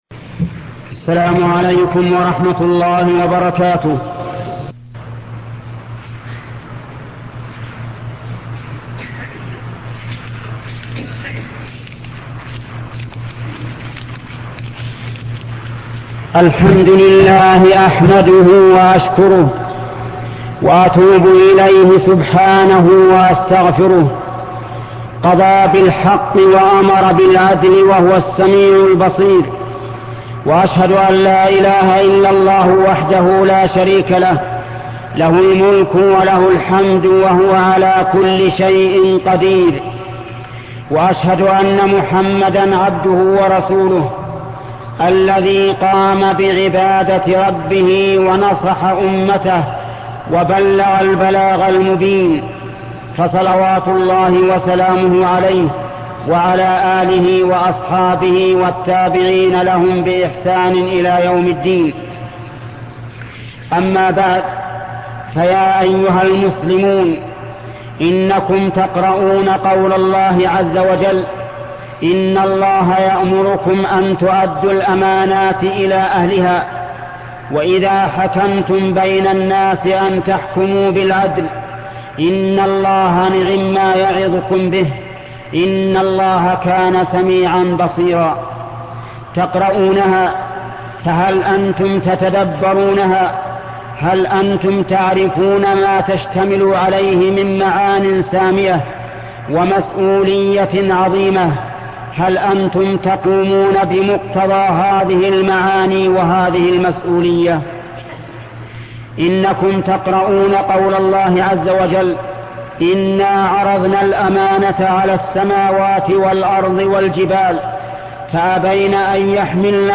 خطبة الأمانة في الإمتحان الشيخ محمد بن صالح العثيمين